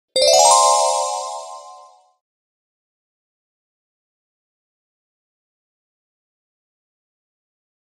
دانلود آهنگ چوب جادو و شعبده بازی 2 از افکت صوتی اشیاء
دانلود صدای چوب جادو و شعبده بازی 2 از ساعد نیوز با لینک مستقیم و کیفیت بالا
جلوه های صوتی